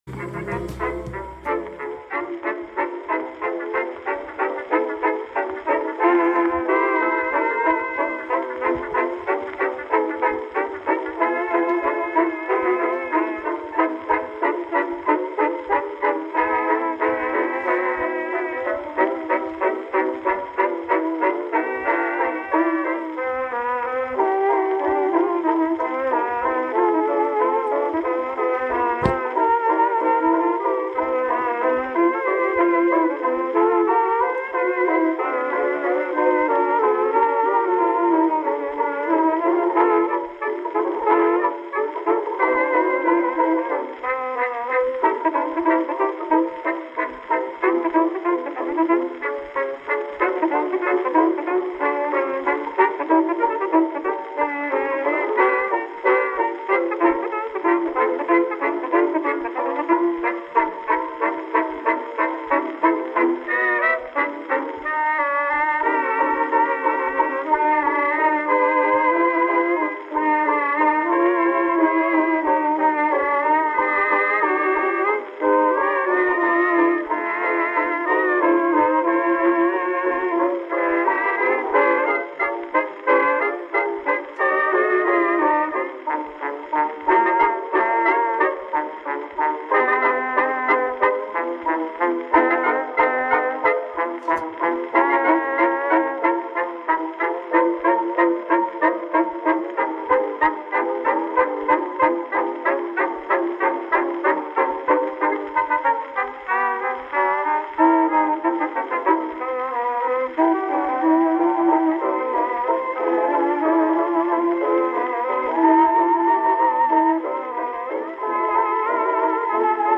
Live from Nutley NJ from Apr 28, 2015